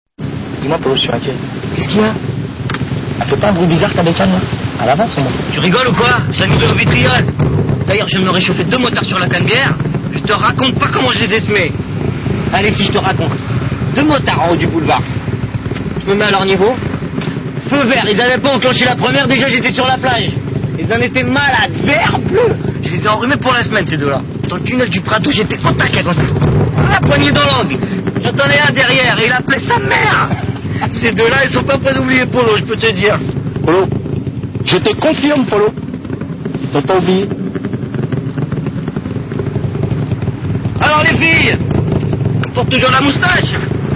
Sons et dialogues du film Taxi
Cliquez sur l�extrait qui vous int�resse, d�sol�, mais pour certains, la qualit� n�est pas au rendez-vous.